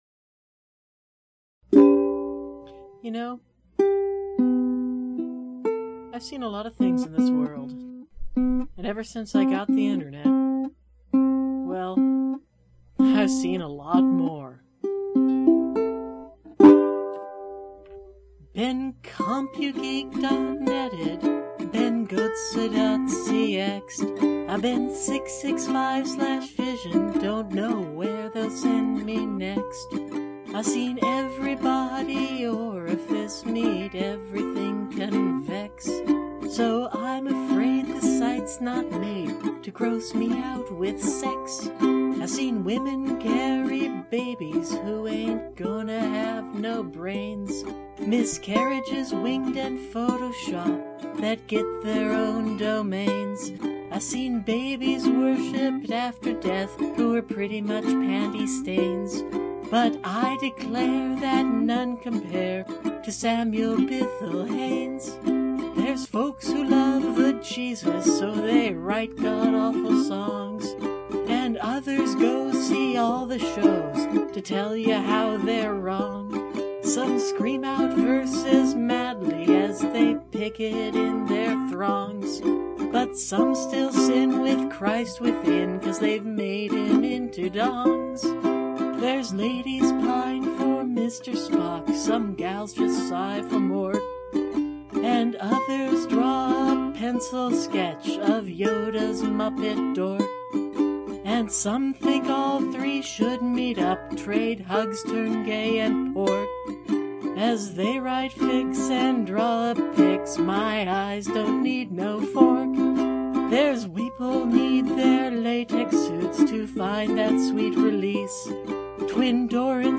I played the musical part on my tenor ukulele, before I left for my vacation/family obligation.
This practice of recording one track at a time combined with your finely honed "chops" as I'm told they say in the business makes for an impressively precise recording.
The voice is something, it's pro, and unique in a very commanding kind of way.